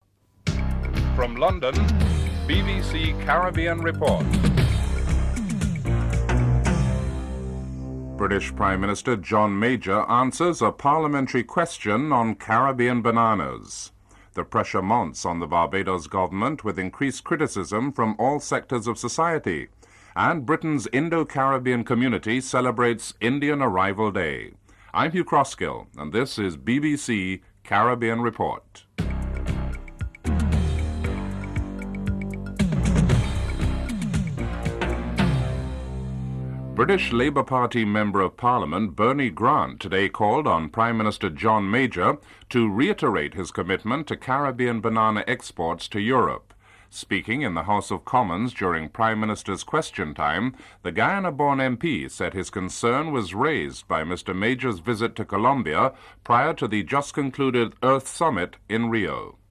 The British Broadcasting Corporation
1. Headlines (00:00-00:38)